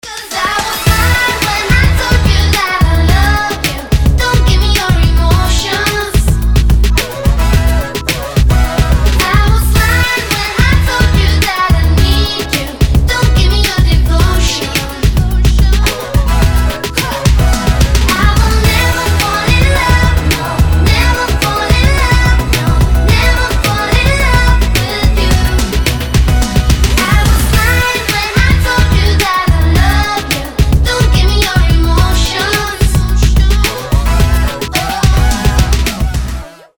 • Качество: 320, Stereo
поп
Electronic
озорные
Electropop
игривые